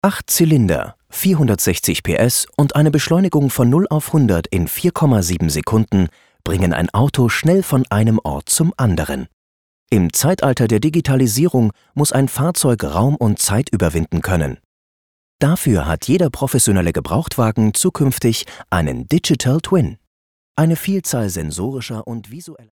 Erfahrener deutscher Sprecher.
Meine Stimme kann warm, beruhigend, bestimmt, dynamisch oder energetisch klingen, so wie Sie es brauchen.
Sprechprobe: Industrie (Muttersprache):
My voice can sound warm, calming, determined, dynamic or energetic as you need it to be.